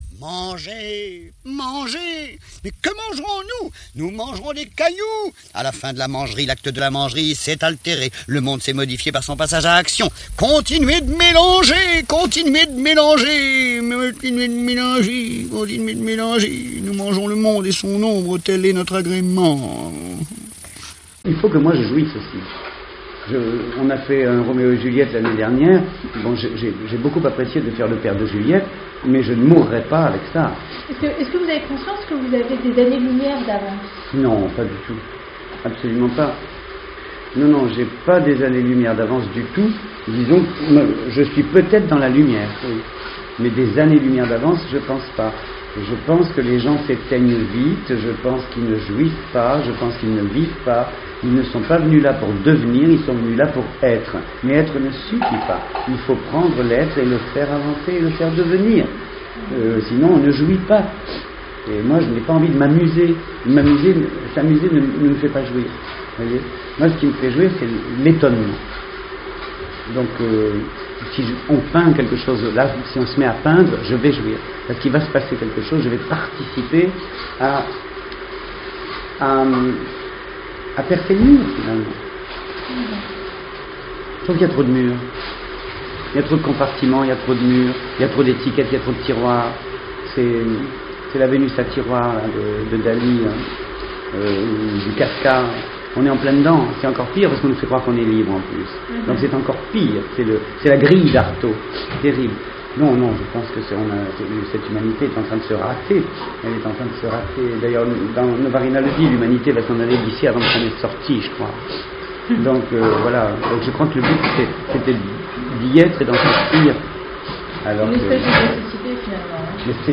Cette partie de l’enregistrement est un peu moins nette (surtout le début), et nous en sommes vraiment désolés. L’environnement sonore (le petit café du théâtre) a été pendant ces quelques minutes un peu plus bruyant, mais franchement cela n’entache que très peu le plaisir qu’on a d’écouter le comédien…